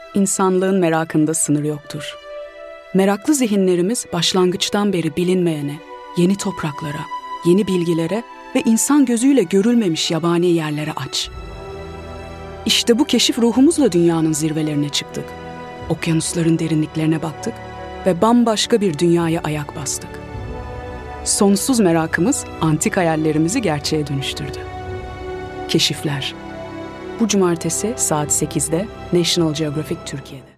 Middle Eastern, Turkish, Female, 20s-30s